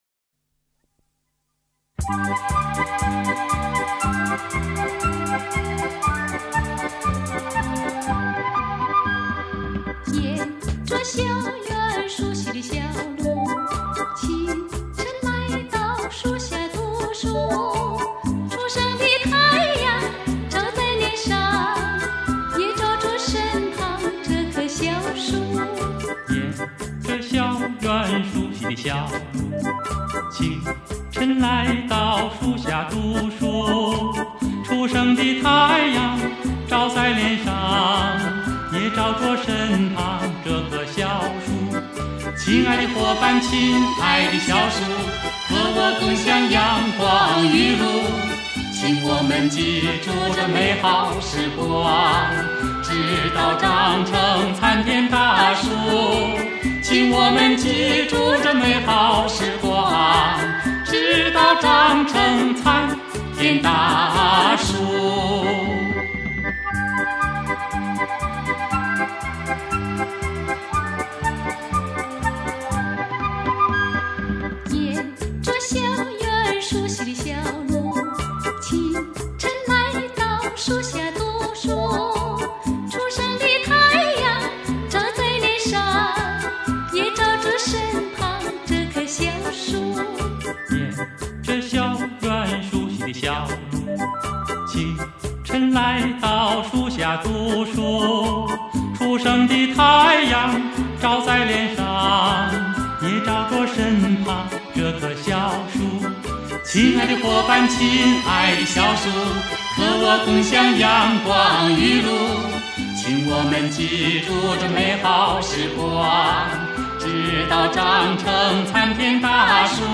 二人的合唱由其和谐、动听、极具魅力成为八十年代以来二人组合的黄金搭档。